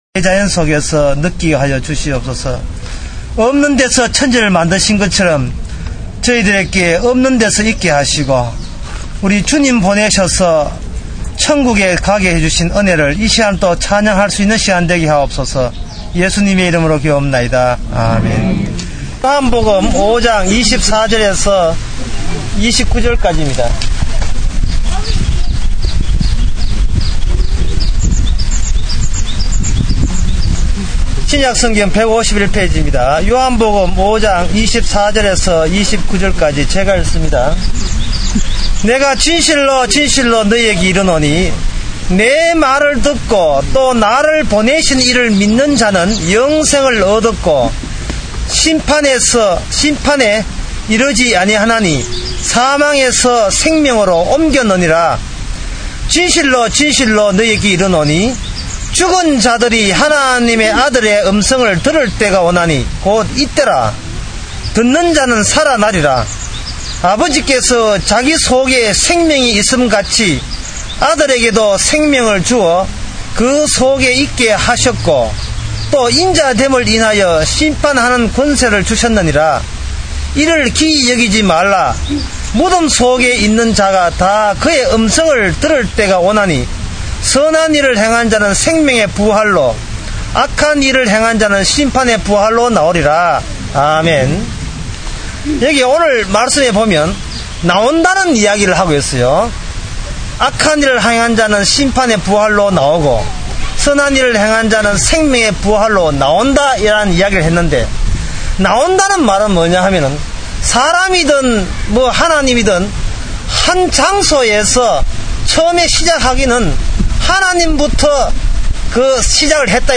신약 설교